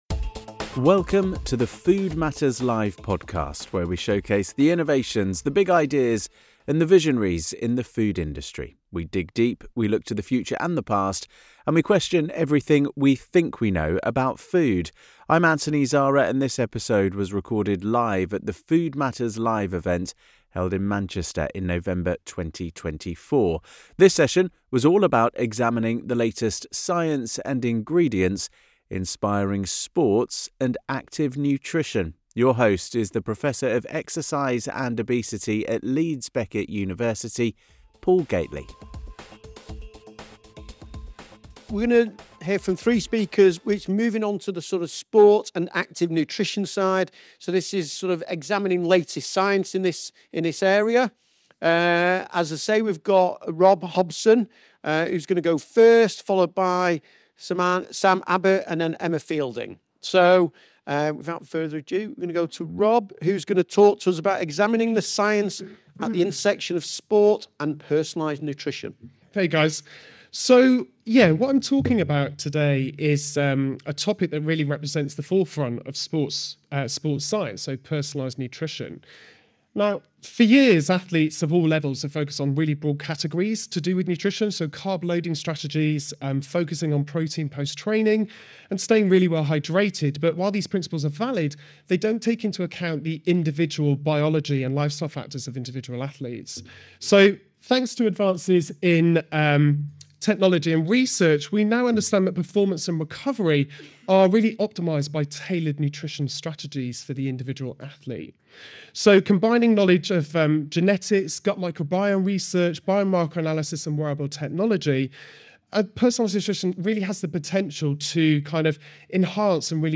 In this episode of the podcast, recorded live at Food Matters Live in Manchester in November 2024, our panel of experts explores the latest research and practical applications of personalised nutrition in sport. From the science of nutrigenomics, to the evolving understanding of the gut microbiome, our speakers discuss the technologies and strategies shaping the future of sports nutrition.